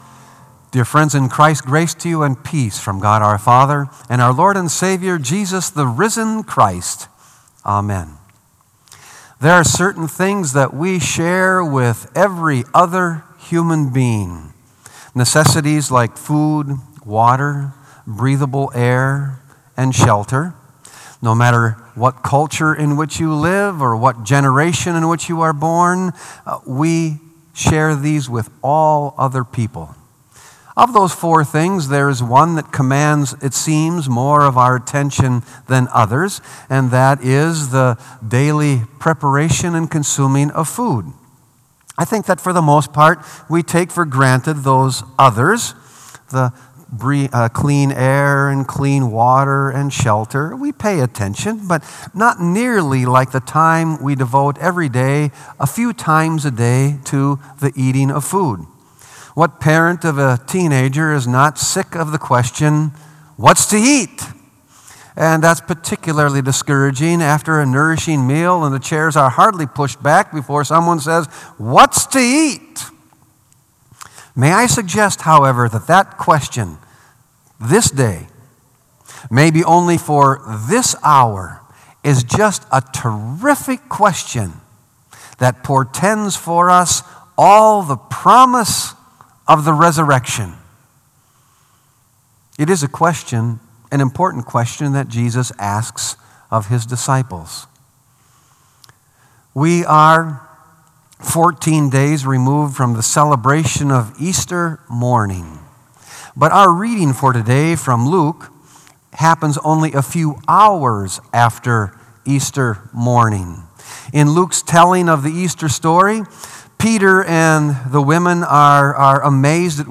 Sermon “What’s to Eat?”